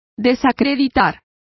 Complete with pronunciation of the translation of debunk.